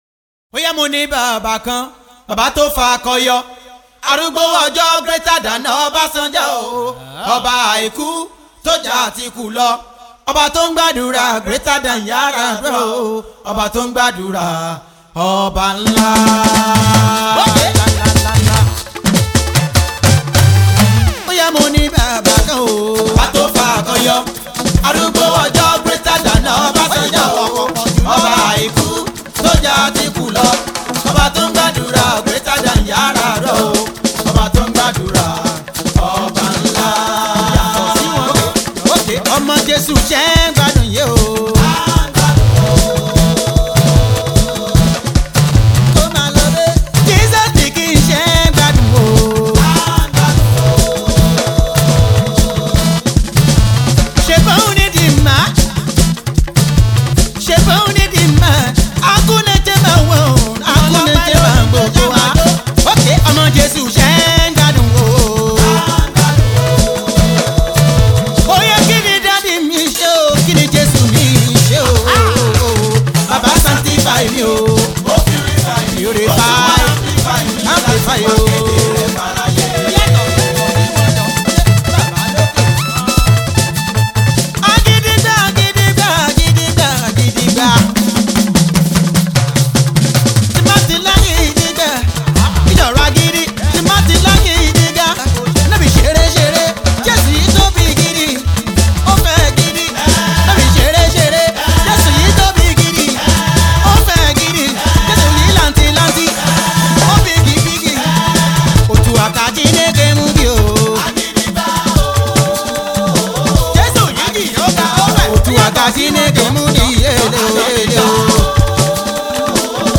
gospel
is a heavily afrocentric tune
switch up the praise tempo